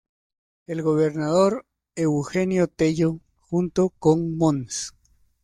Pronounced as (IPA) /ˈxunto/